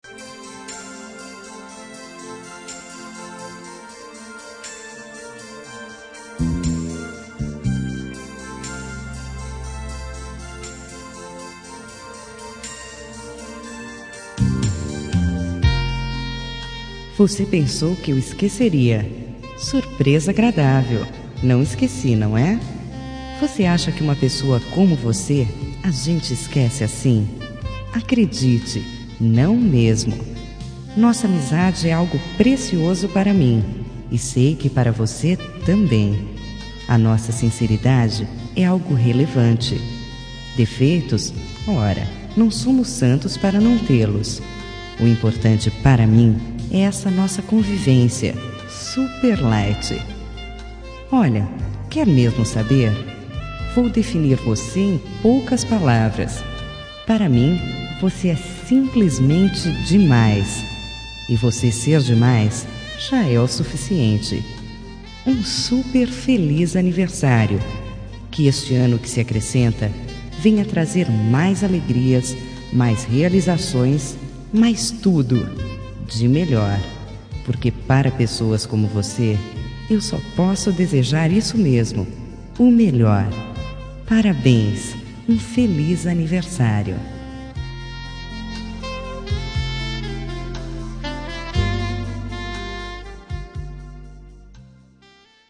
Telemensagem de Aniversário de Pessoa Especial – Voz Feminina – Cód: 1881 Achou que tinha esquecido?